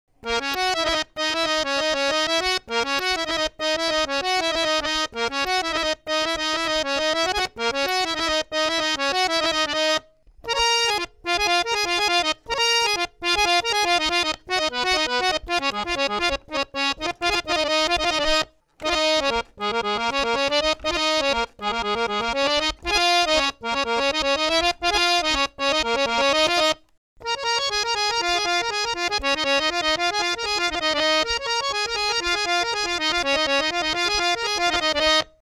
Harmonium | Amit Sharma Productions
170 Punjabi style harmonium best loops Professionally recorded at various Key and Bpm
harmonium.mp3